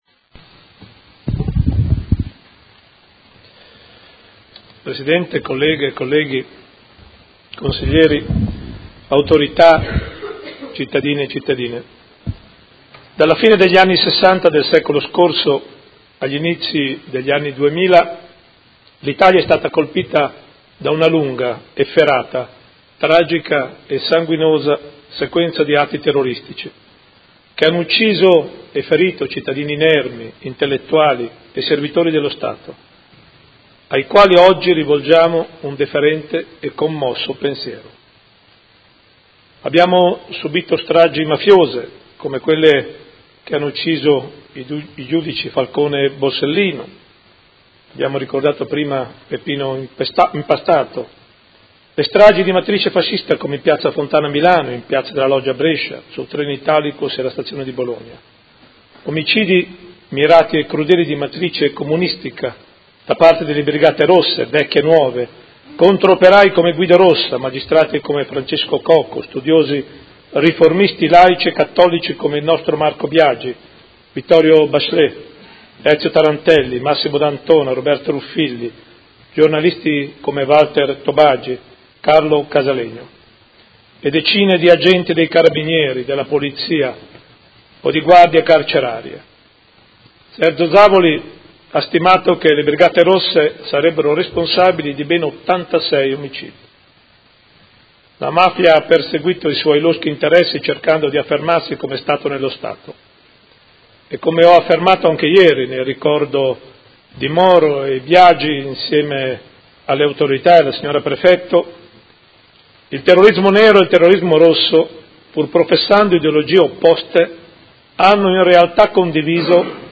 Gian Carlo Muzzarelli — Sito Audio Consiglio Comunale
Seduta del 10/05/2018 Commemorazione giornata dedicata alle vittime del terrorismo.